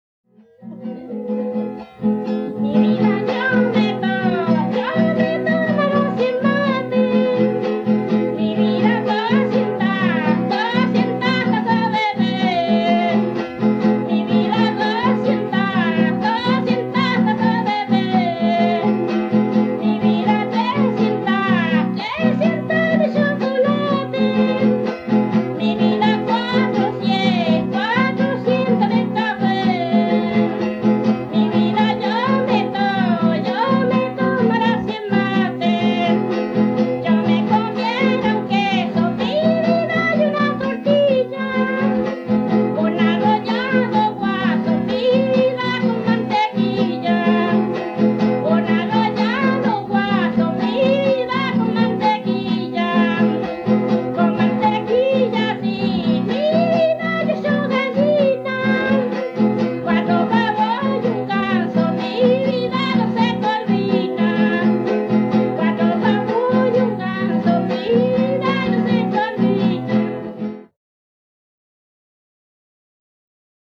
quien se acompaña con una guitarra afinada con la tercera alta.
Música chilena
Folklore
Cueca